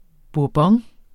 Udtale [ buɐ̯ˈbʌŋ ]